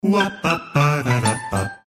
• Качество: 320, Stereo
голосовые
Мужской озорной голос